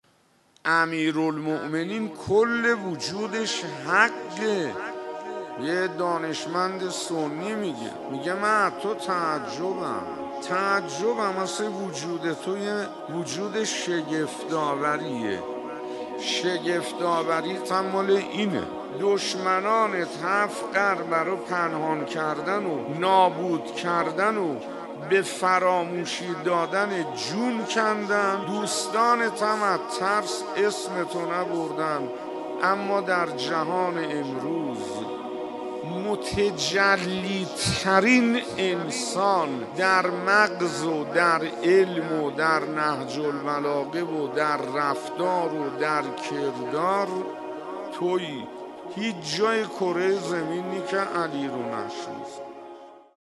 شیخ حسین انصاریان در یکی از سخنرانی‌هایش در مسجد امیر(ع) می‌گوید: کل وجود امیرالمؤمنین(ع) حق است. یک دانشمند سنی می‌گوید من از وجود شگفت‌آور علی(ع) تعجب می‌کنم که دشمنان او هفت قرن برای نابود کردن و فراموش کردنش تلاش کردند؛ اما در جهان امروز متجلی‌ترین انسان، در مغز، علم، نهج‌البلاغه، رفتار و کردار علی(ع) است.